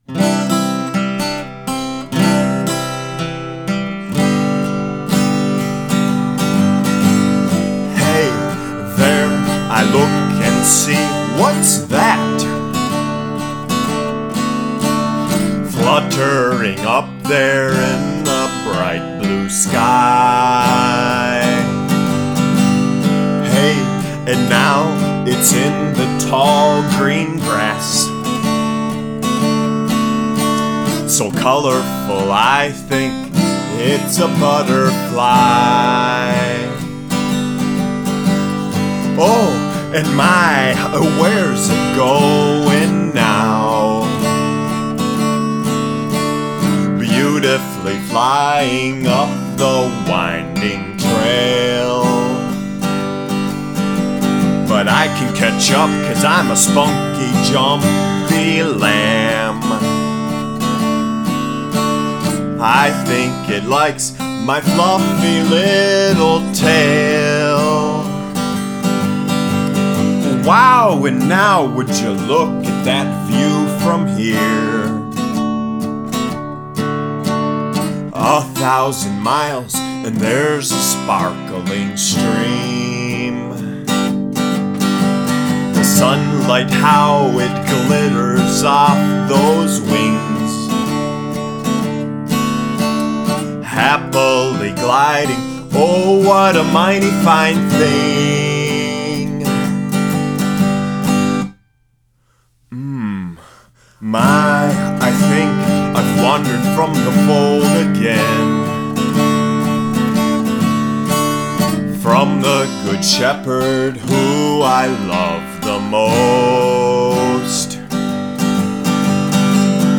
written 2002 MP3 RA WMP This is a song for all ages that is made in the style of a children's song.